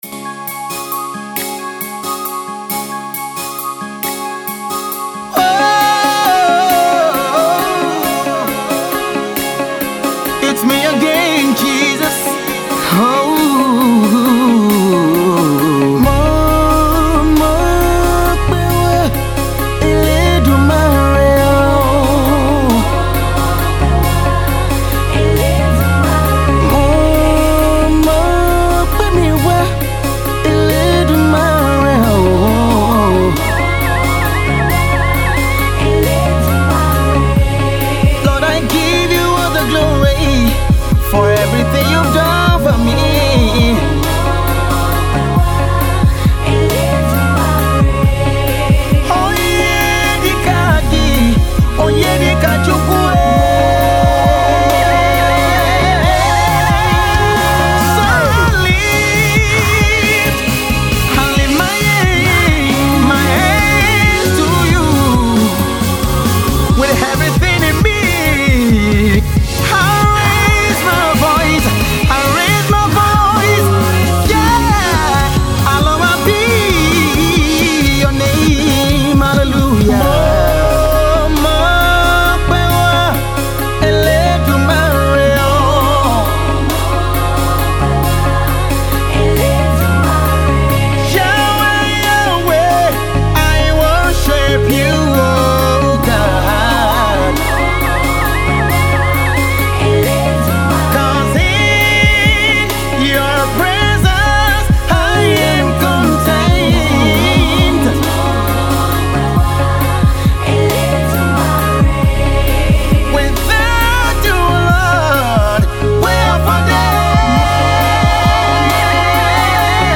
gospel
worship single
make your worship of God mellow